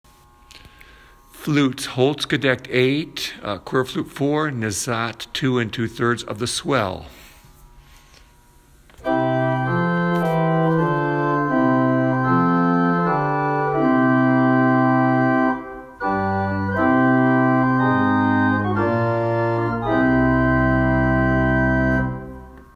Built in 1970 by Blanchard Pipe Organ, this well-loved organ offers clear tone and is voiced for fullness and richness.
This organ offers a beautifully rich and versatile tonal gamut for accompaniment purposes to a small or midsize congregation.
Swell-flutes-lake-ave.m4a